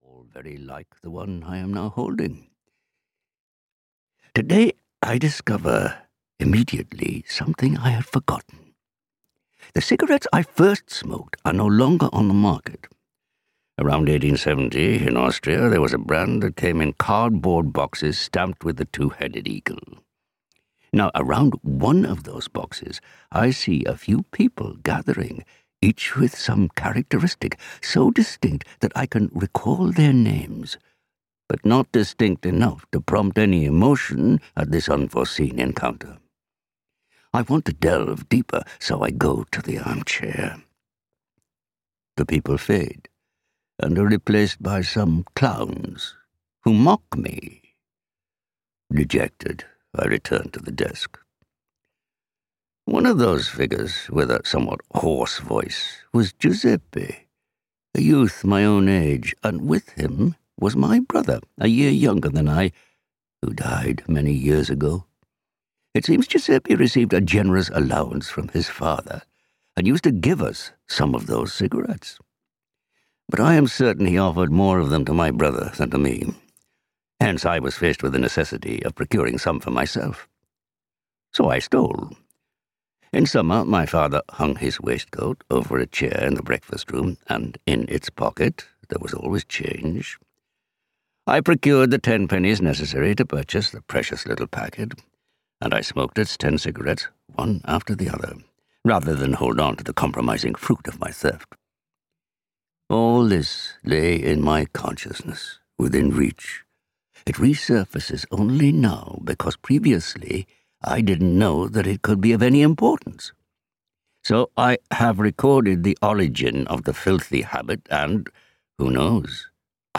Audio knihaZeno's Conscience (EN)
Ukázka z knihy